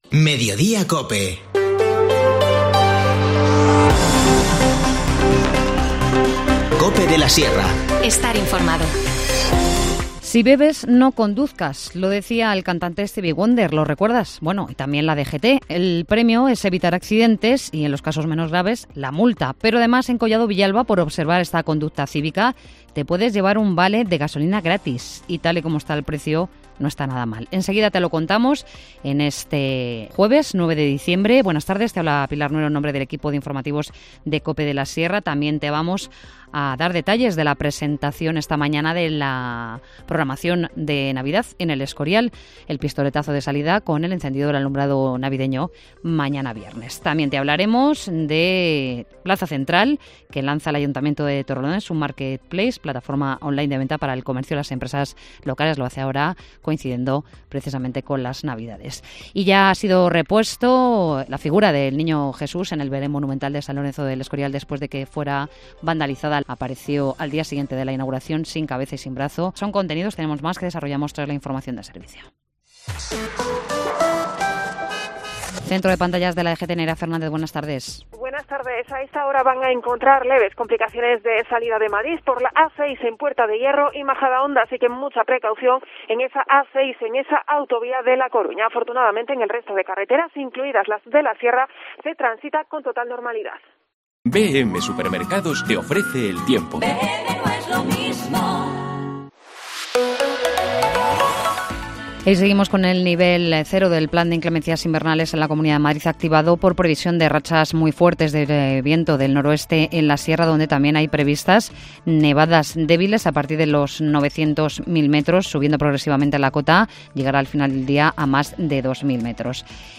Informativo Mediodía 9 diciembre